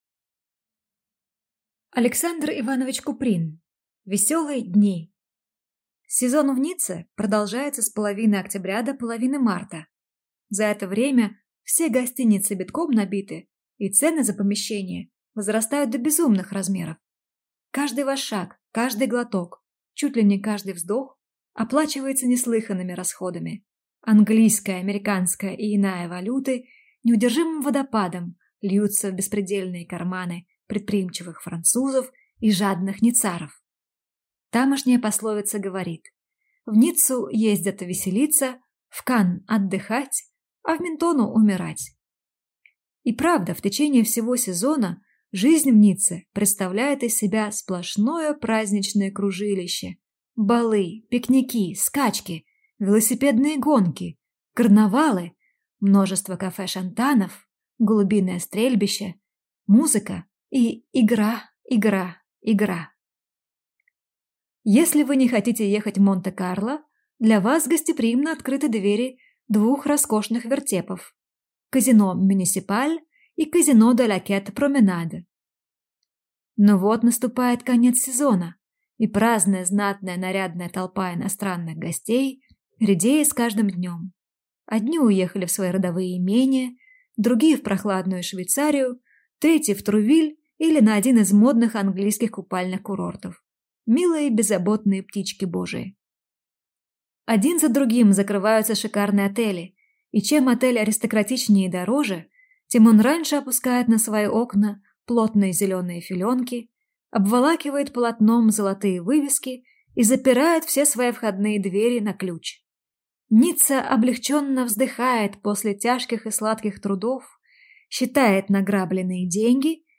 Аудиокнига Веселые дни | Библиотека аудиокниг